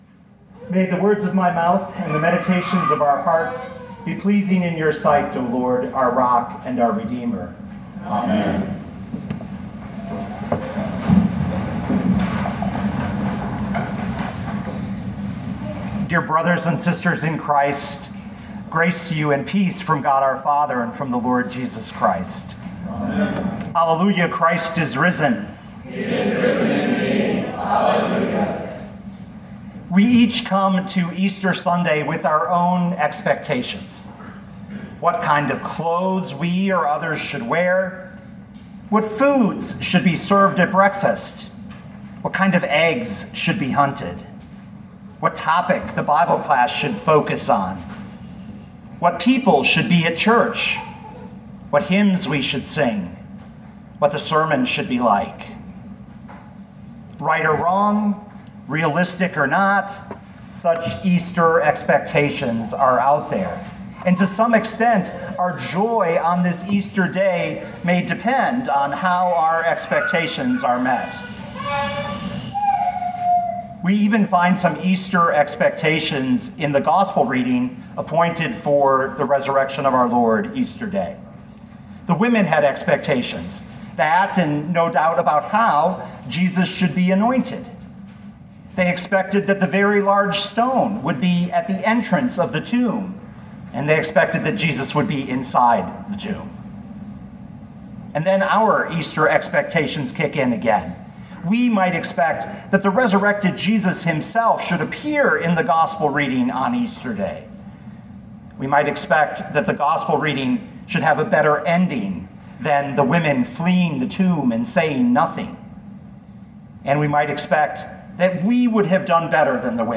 easter-expectations.mp3